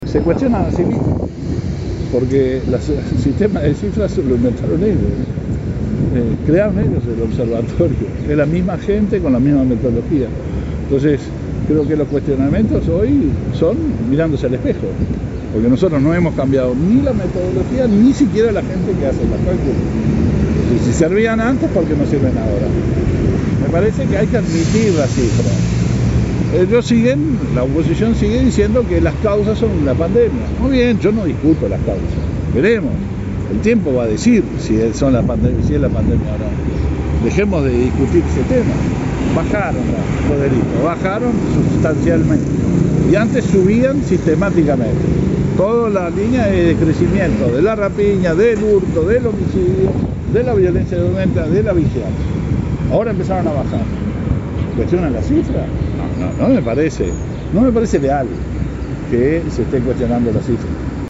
Ahora empezaron a bajar y cuestionan las cifras, no me parece leal que se cuestionen las cifras”, señaló el ministro en rueda de prensa.
Escuche a Heber aquí: